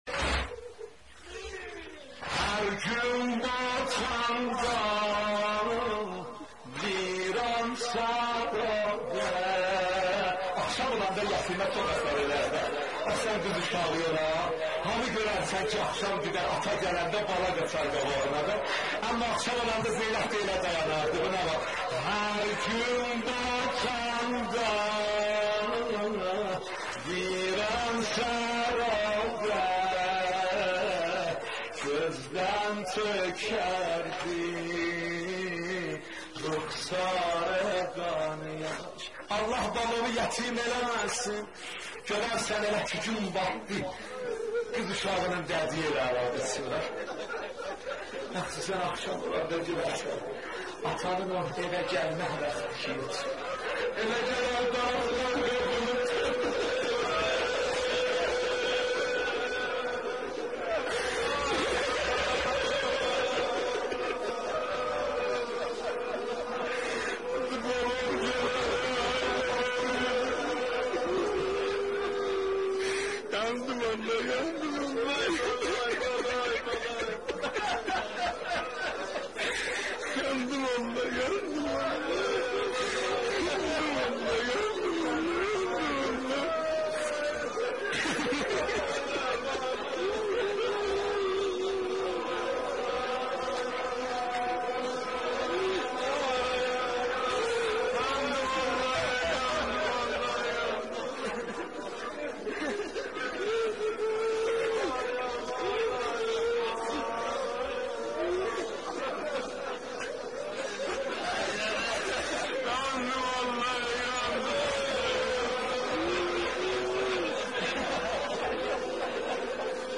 مداحی ترکی محرم
گلچین مداحی ترکی محرم استودیویی